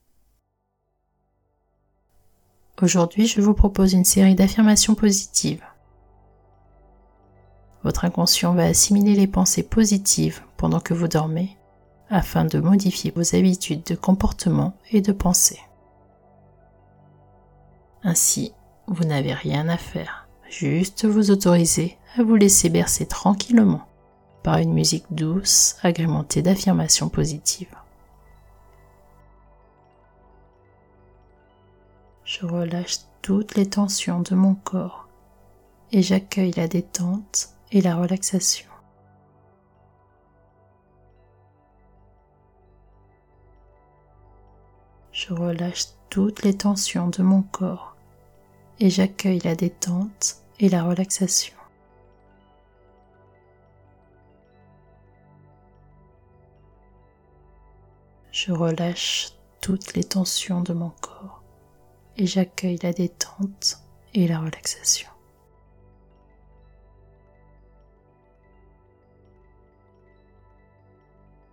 extrait-affirmations-positives-dormir-profondement.mp3